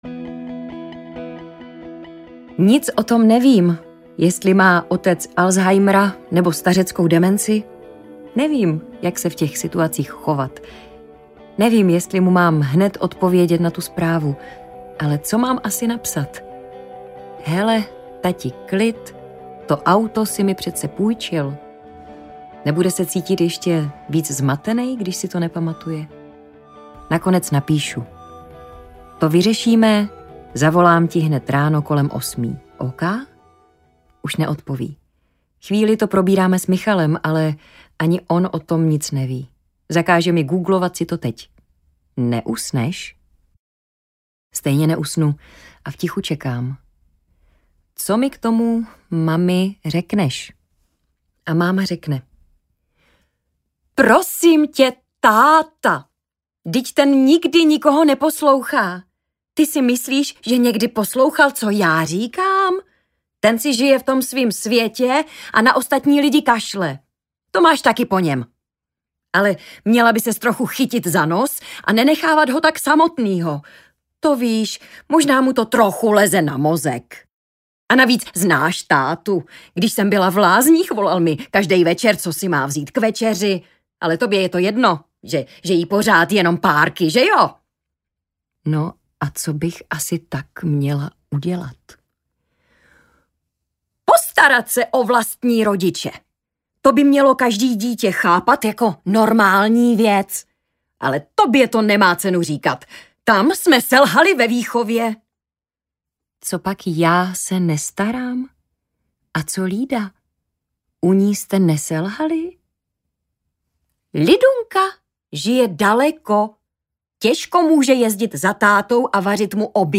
Nikdo není sám audiokniha
Ukázka z knihy
• InterpretAndrea Buršová, Tereza Marečková, Pavel Neškudla